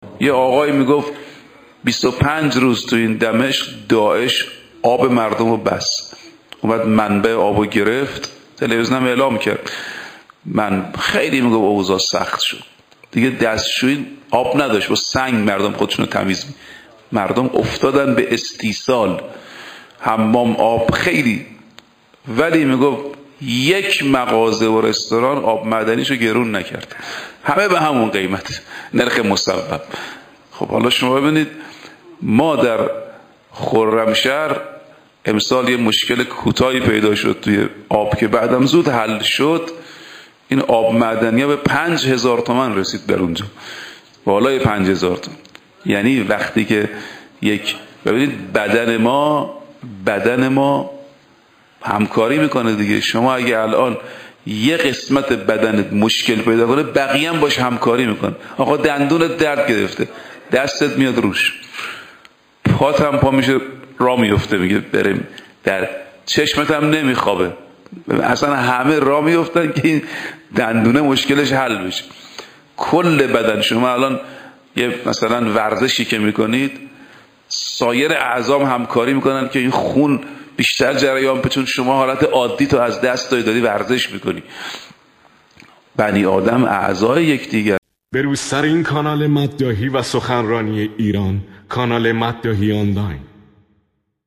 صوت/ سخنرانی بسیار شنیدنی درباره انصاف